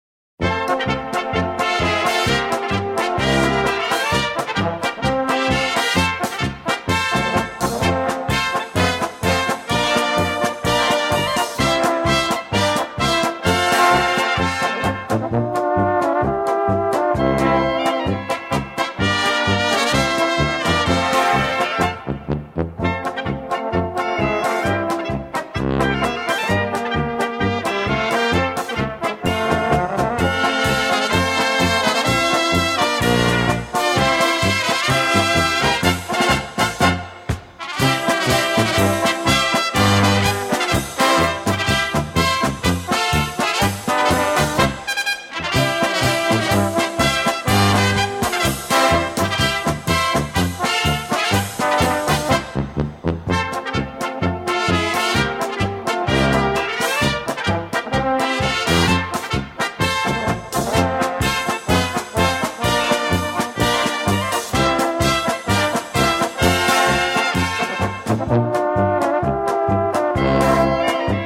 Gattung: Polka
Besetzung: Blasorchester